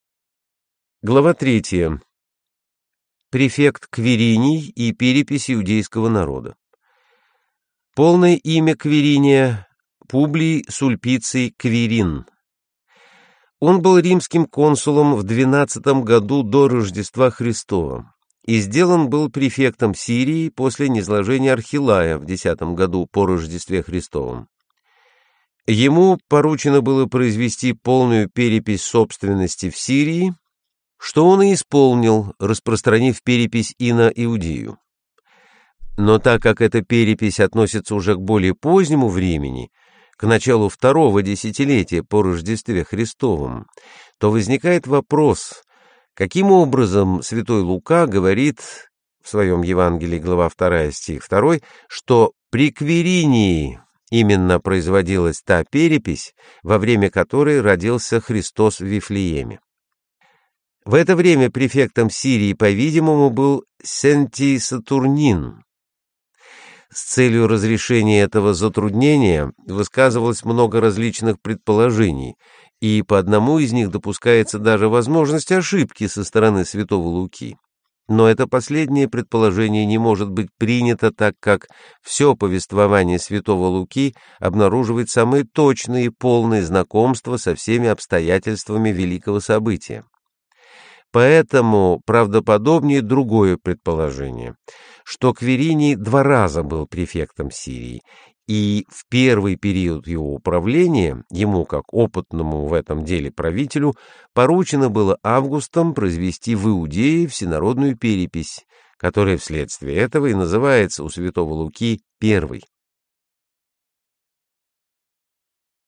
Аудиокнига Библейские истории Нового Завета: Апостольский век | Библиотека аудиокниг